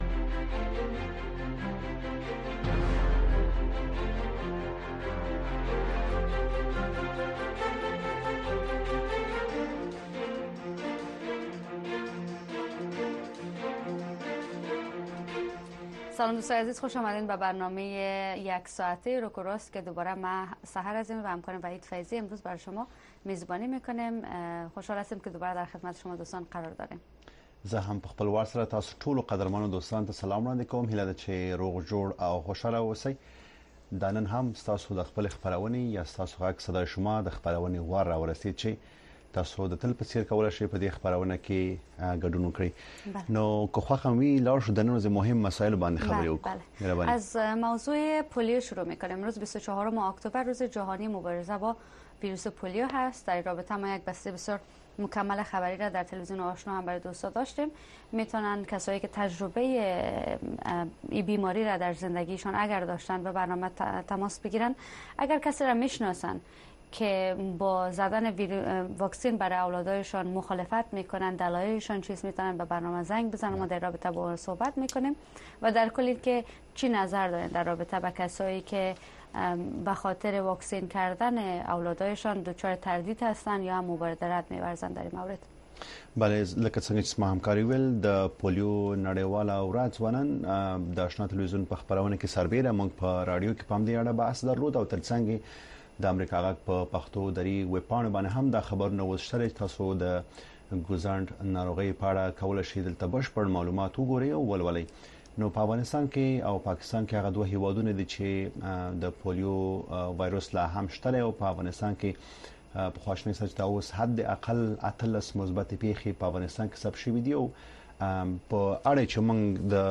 ستاسې غږ خپرونه کې د امریکا غږ د اشنا رادیو اوریدونکي په مستقیمه توګه له مونږ سره اړیکه نیسي او د خپرونې د چلوونکو او اوریدونکو سره خپل نظر، اندیښنې او شکایتونه شریکوي. دا خپرونه په ژوندۍ بڼه د افغانستان په وخت د شپې د ۹:۳۰ تر ۱۰:۳۰ بجو پورې خپریږي.